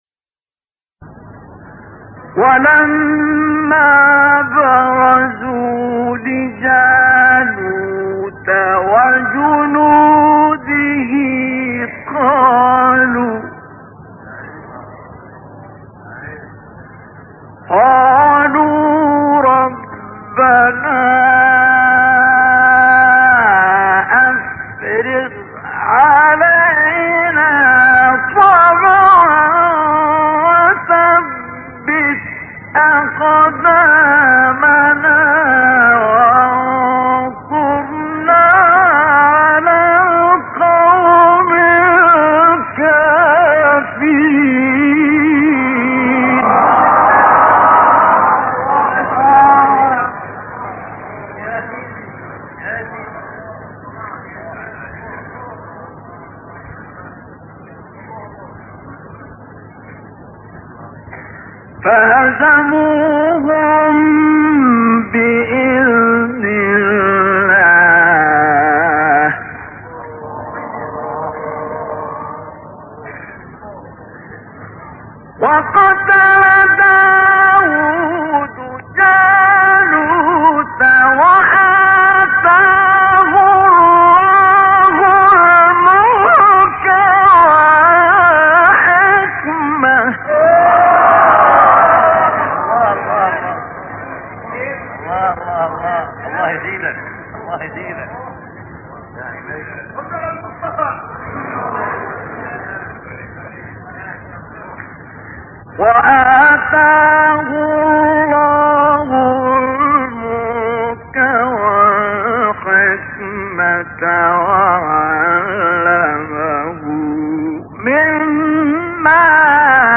سایت قرآن کلام نورانی - نهاوند مصطفی اسماعیل (4).mp3
سایت-قرآن-کلام-نورانی-نهاوند-مصطفی-اسماعیل-4.mp3